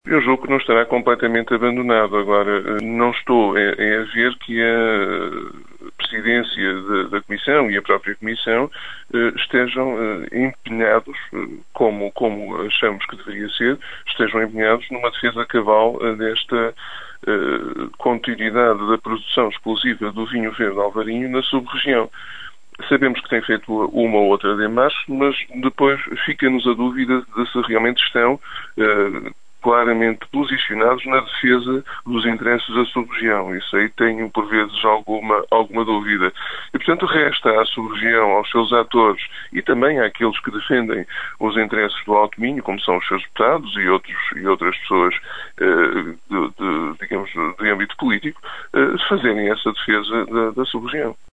Manuel Baptista, presidente da Câmara de Melgaço, reforça que a Comissão de Viticultura da Região dos Vinhos Verdes ainda não abandonou a ideia e, por isso, é importante que todos os agentes decisores e políticos do Alto Minho façam lobby em favor da manutenção da região restrita apenas aos concelhos de Monção e Melgaço.